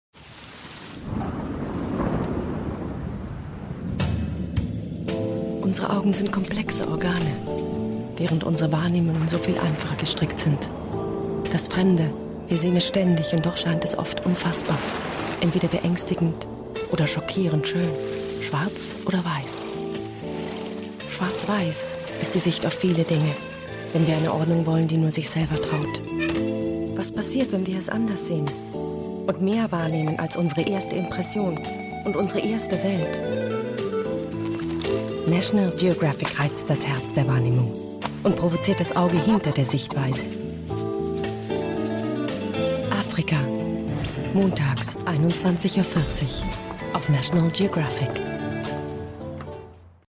deutsche Sprecherin mit warmer, sinnlicher, sehr wandlungsfähiger Stimme, Stimmlage mittel / tief,
Sprechprobe: Industrie (Muttersprache):